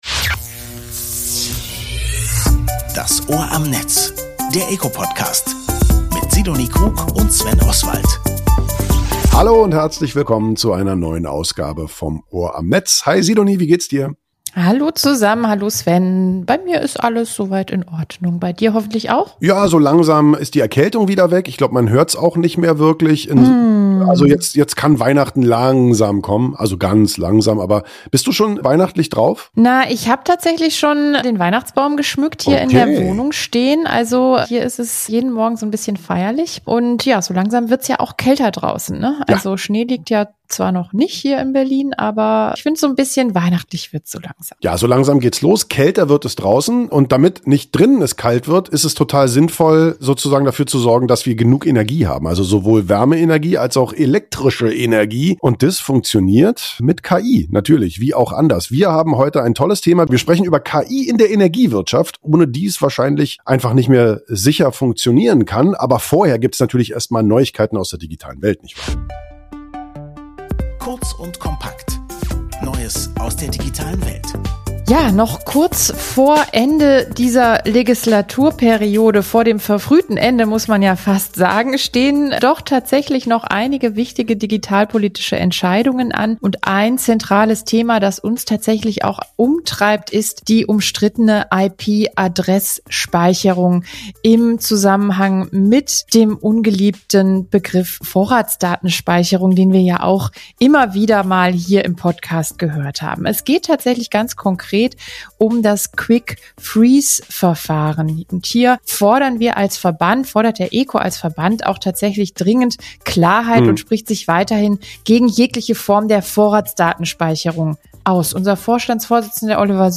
erklärt im Interview